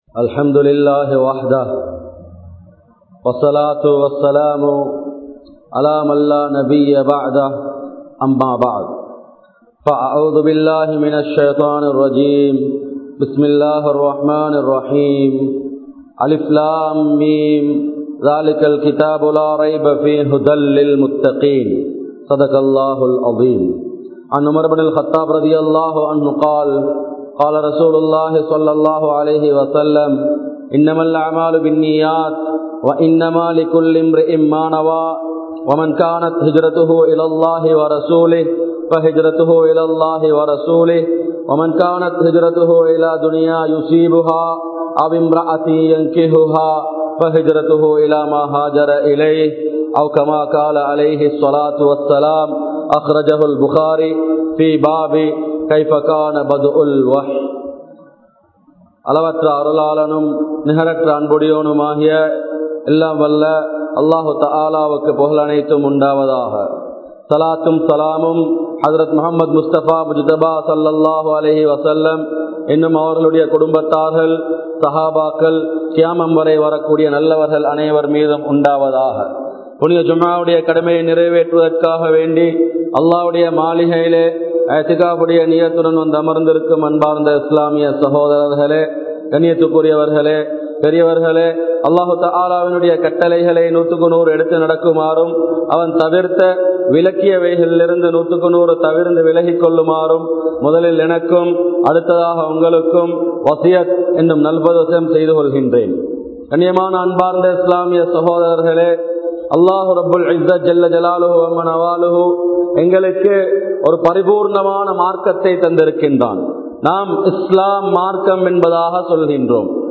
Islaththil Ungalukku Enna Illai? (இஸ்லாத்தில் உங்களுக்கு என்ன இல்லை?) | Audio Bayans | All Ceylon Muslim Youth Community | Addalaichenai
Grand Jumua Masjidh